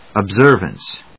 音節ob・ser・vance 発音記号・読み方
/əbzˈɚːv(ə)ns(米国英語), ʌˈbzɜ:vʌns(英国英語)/